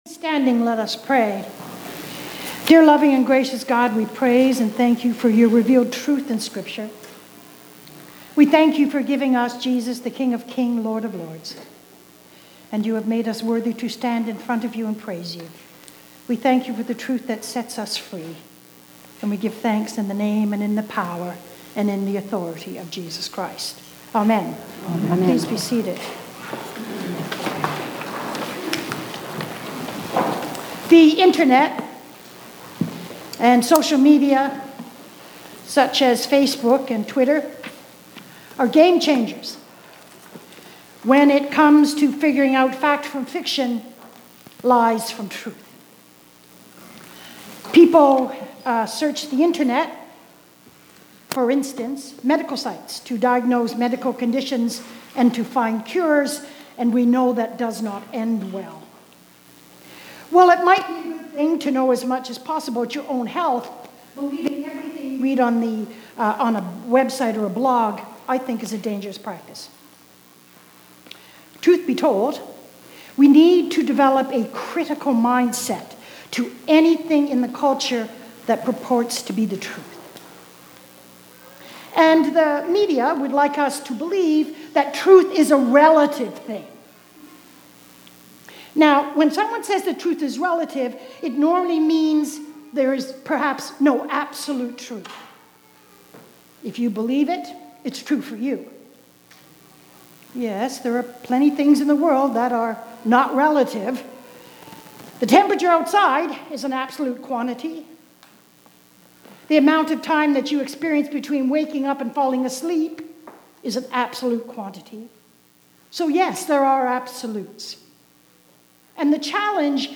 Sermons | St. David and St. Paul Anglican Church